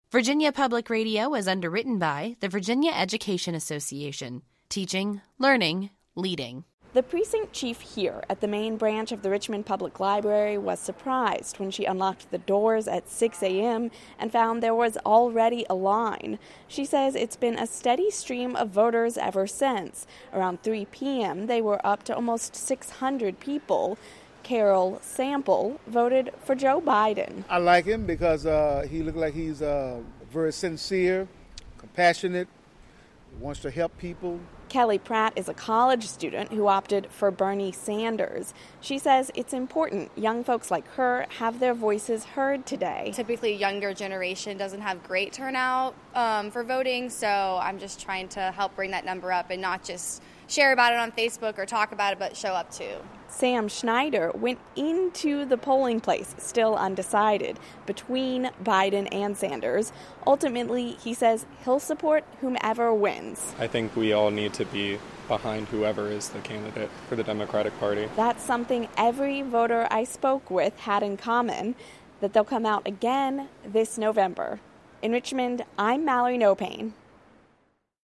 chatted with some of those voters at a polling place in downtown Richmond.